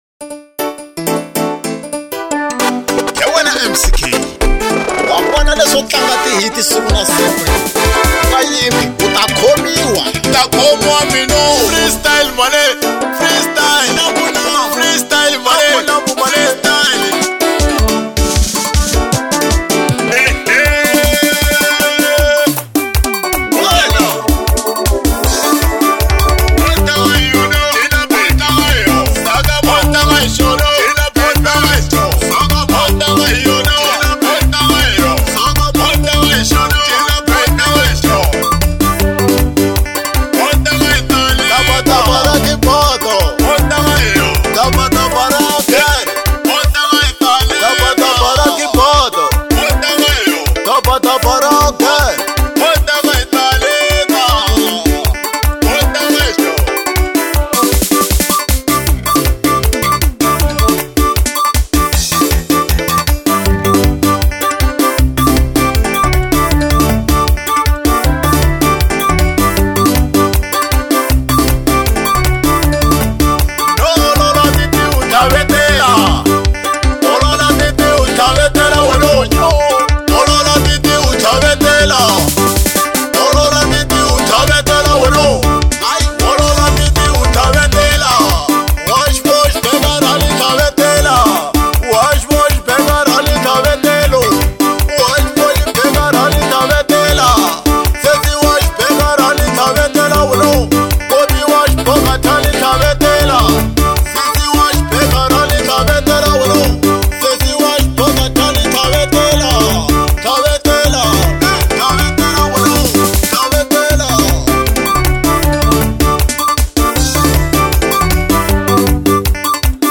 04:31 Genre : Xitsonga Size